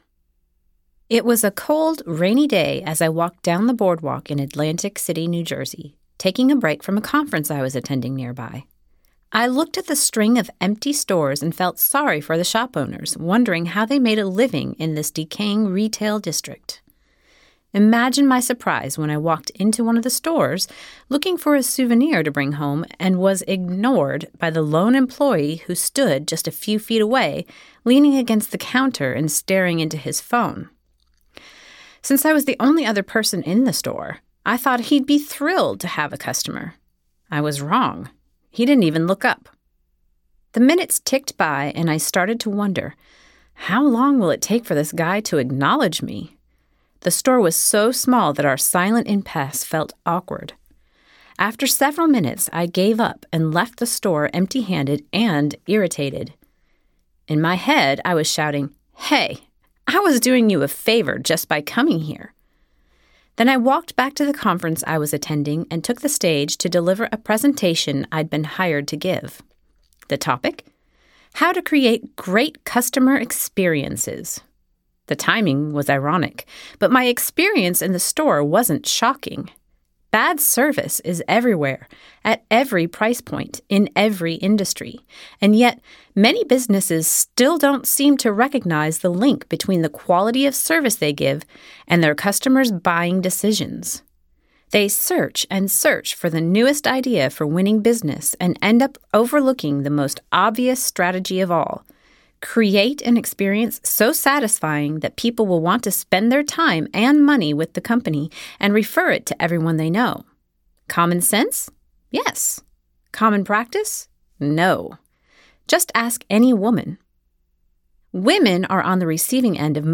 Winning Her Business Audiobook
Narrator